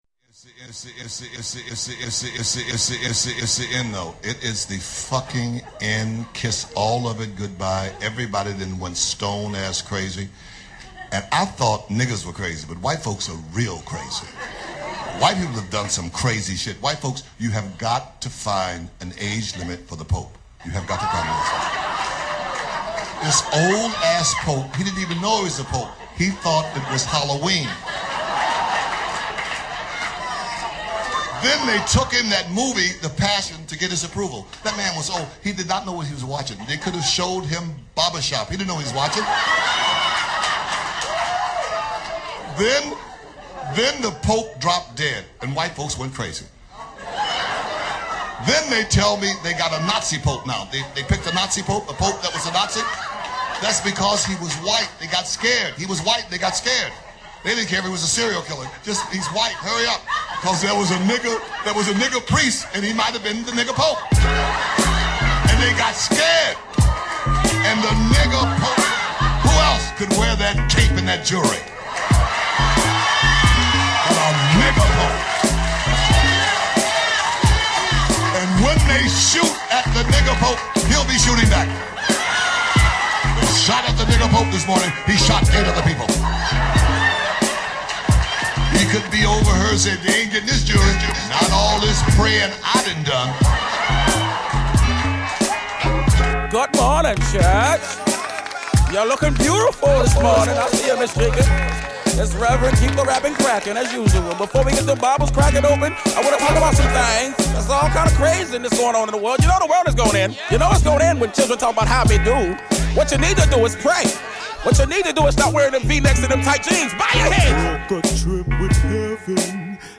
Vous allez vous croire en boite ,carré VIP , à Madrid.